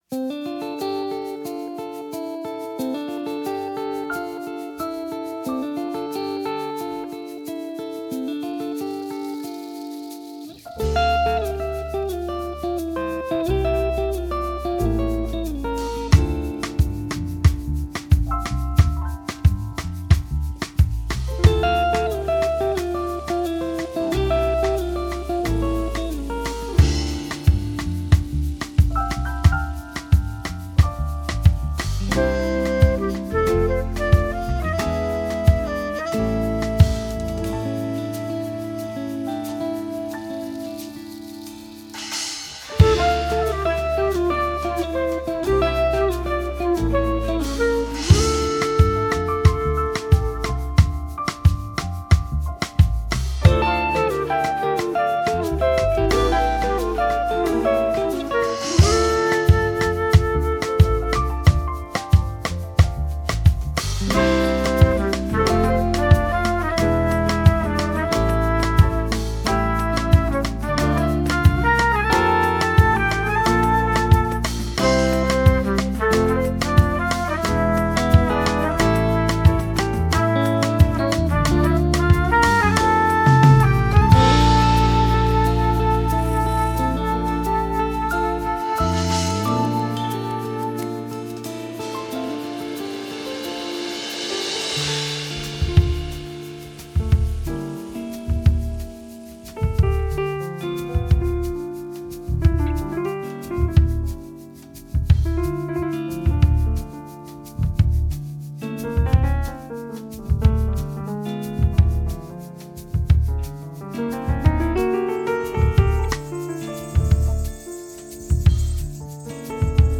Jazz Album
delivering music that feels both expansive and intimate